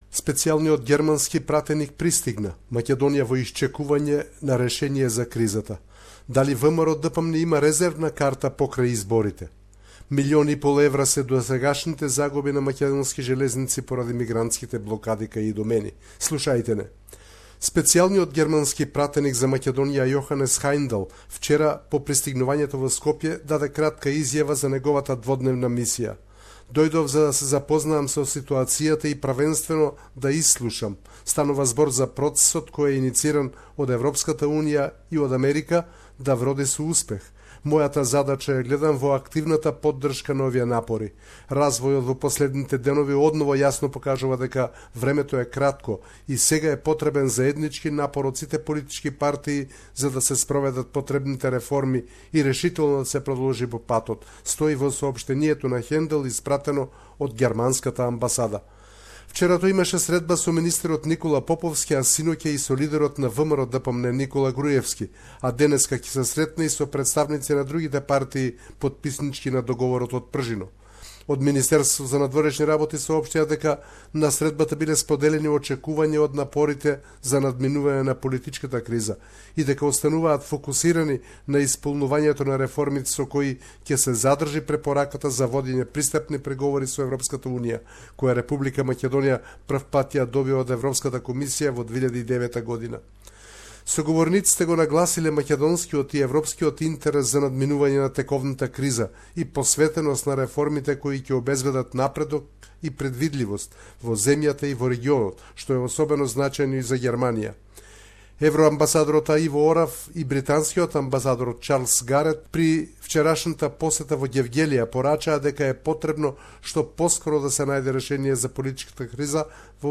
reporting from Macedonia.